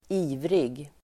Ladda ner uttalet
Uttal: [²'i:vrig]